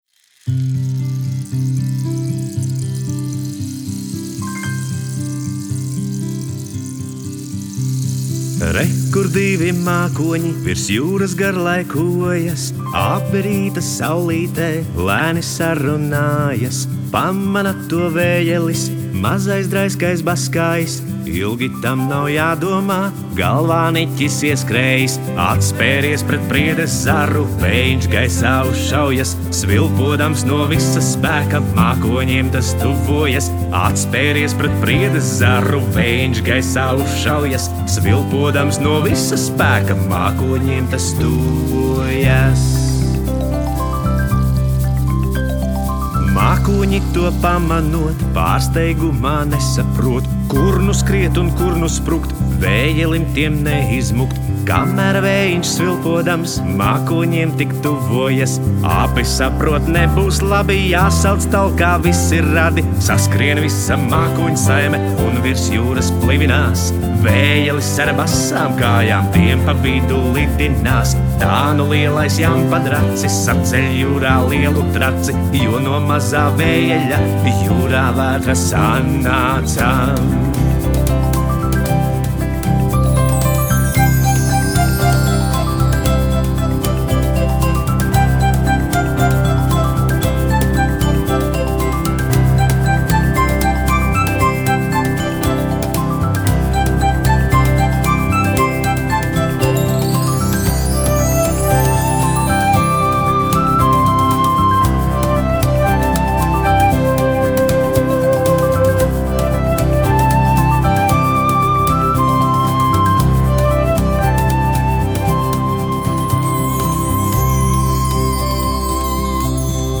Dziesmas un dziesmu pavadījumi.
taustiņi
ģitāra
perkusijas.